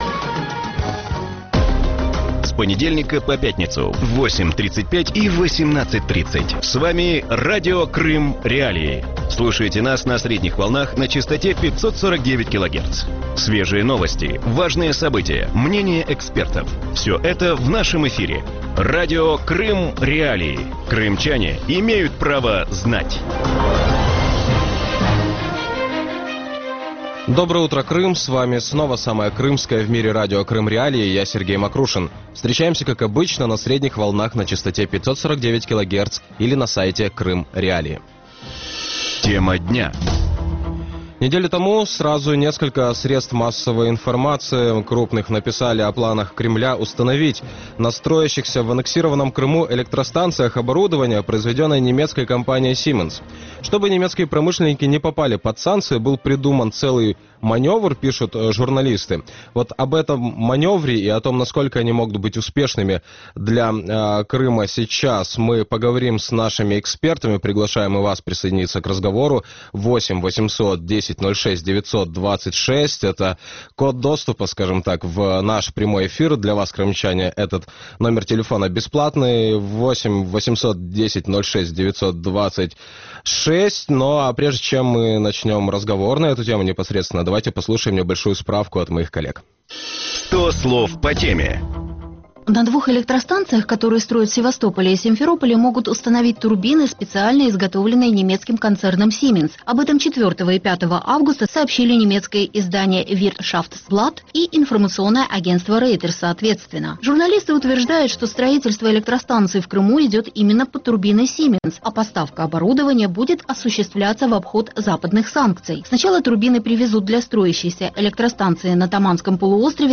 Утром в эфире Радио Крым.Реалии говорят о попытке немецкой компании Siemens поставить в аннексированный Крым оборудование для местных ТЭС. Действительно ли речь идет о способе обойти санкции?